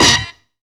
NIGHT SAX.wav